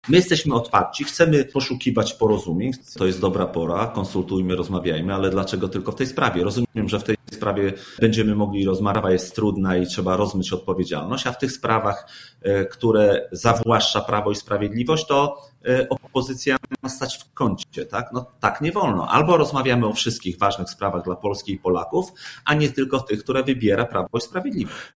Poseł Waldemar Sługocki, przewodniczący Platformy Obywatelskiej w województwie lubuskim, który był dziś gościem „Rozmowy po 9”, odniósł się do tej inicjatywy: